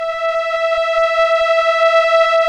Index of /90_sSampleCDs/Keyboards of The 60's and 70's - CD1/STR_Elka Strings/STR_Elka Cellos
STR_ElkaVcE_6.wav